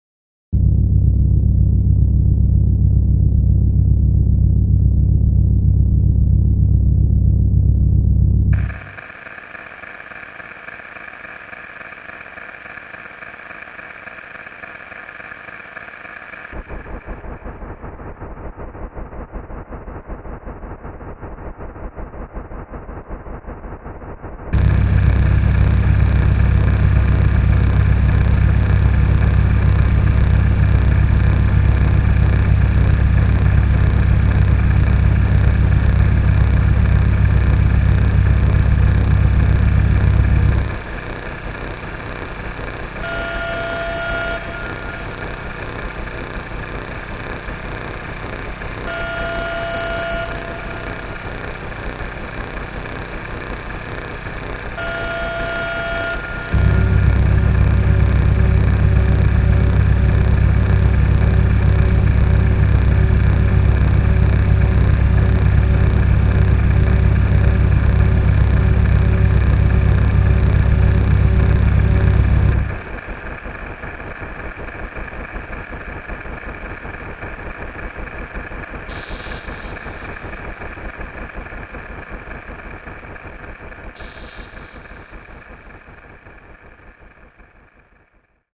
45 MB, 166 various sounds for creating machinery ambience.